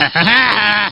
Line of Krunch in Diddy Kong Racing.